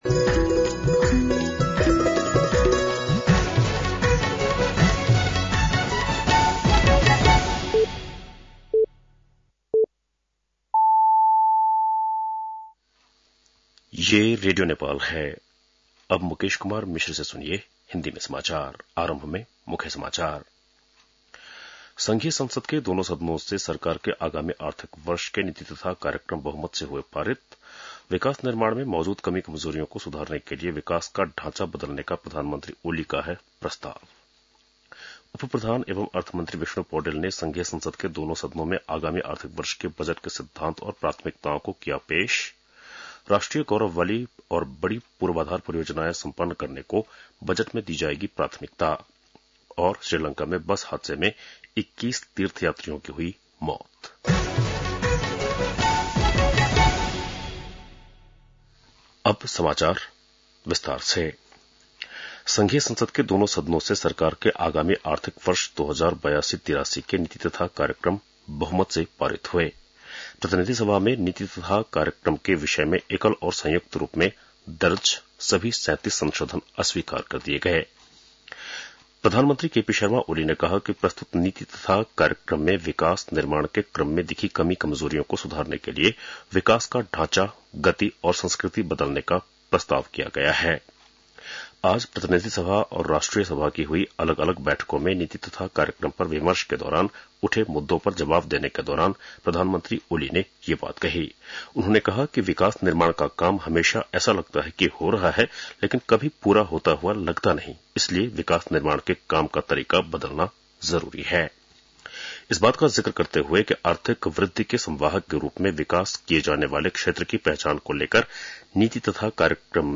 बेलुकी १० बजेको हिन्दी समाचार : २८ वैशाख , २०८२
10-PM-Hindi-NEWS-1-1.mp3